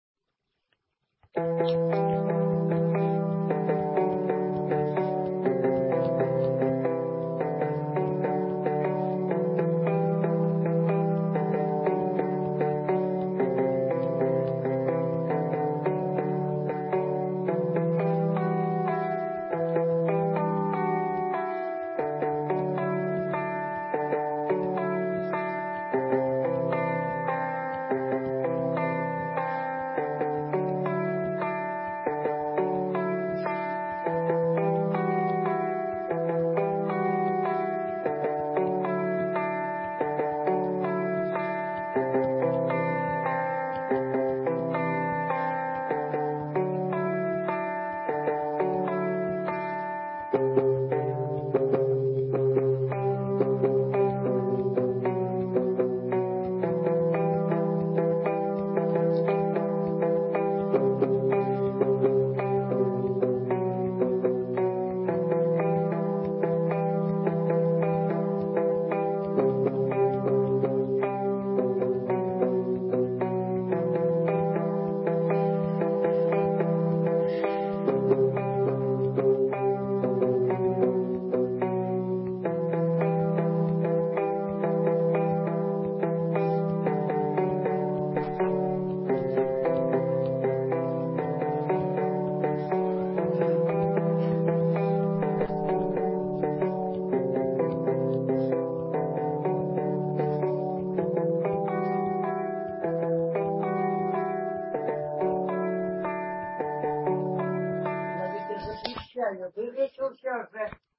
Наконец то в электричестве